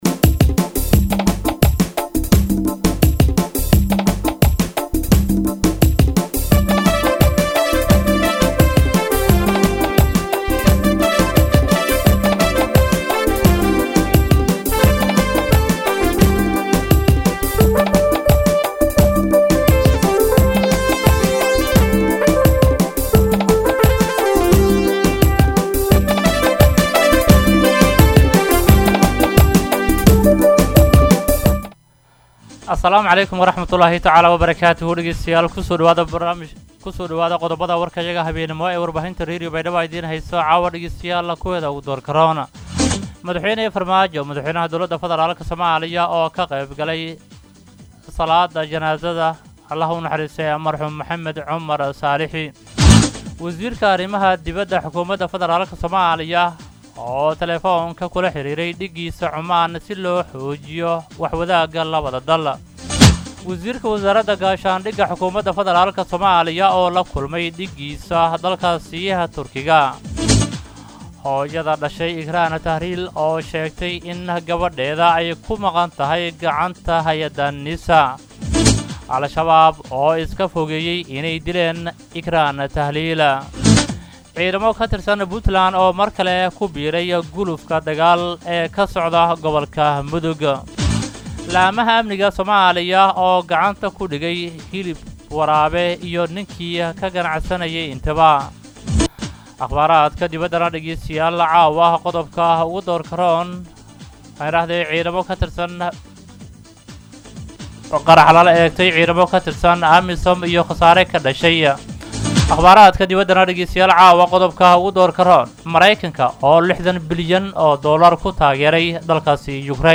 DHAGEYSO:-Warka Habenimo Radio Baidoa 3-9-2021